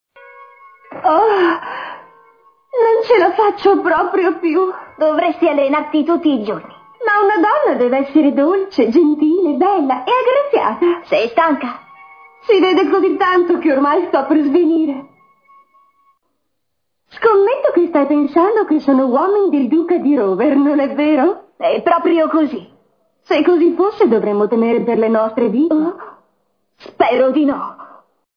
nel cartone animato "Kate & Julie", in cui doppia Julie.